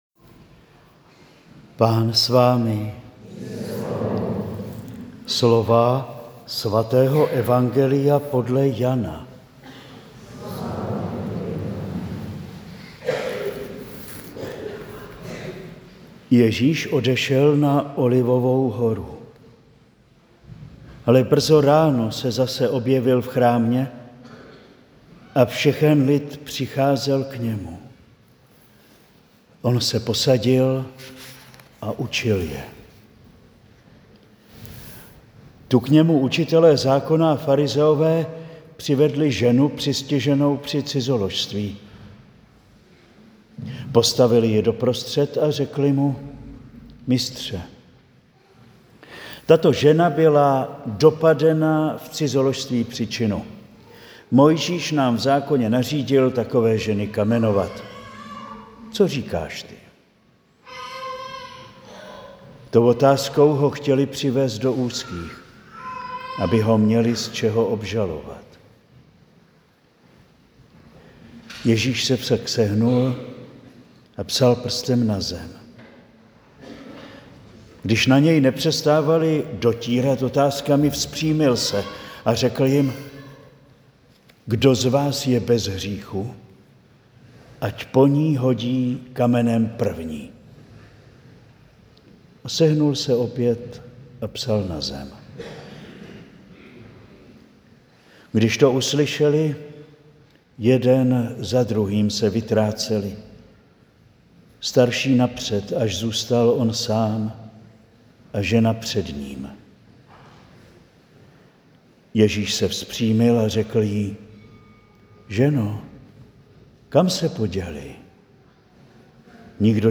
Záznam homilie z 5. neděle postní (C) ze dne 6. 4. 2025 si můžete stáhnout na tomto odkazu.